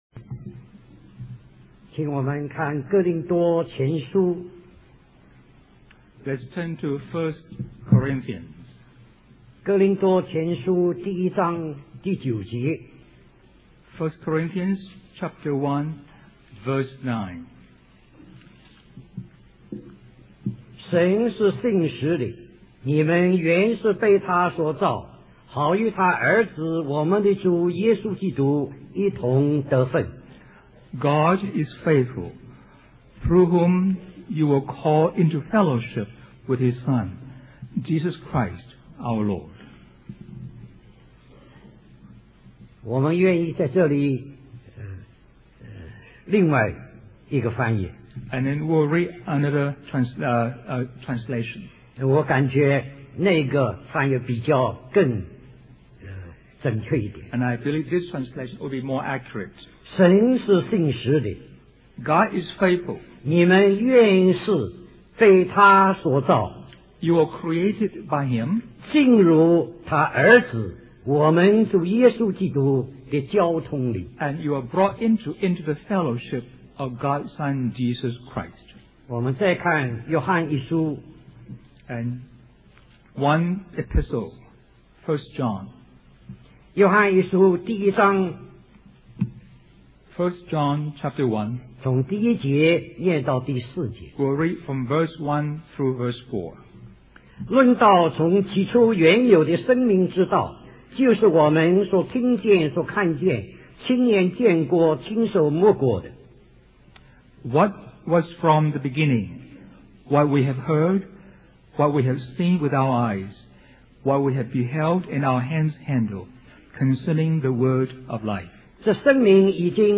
A collection of Christ focused messages published by the Christian Testimony Ministry in Richmond, VA.
Special Conference For Service, Hong Kong